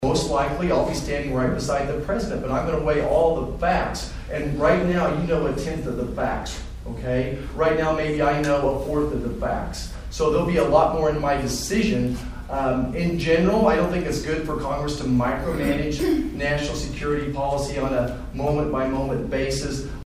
MANHATTAN — Congressman Roger Marshall was back in Manhattan Saturday, hosting a town hall discussion with about two dozen constituents at the Sunset Zoo’s Nature Exploration Place.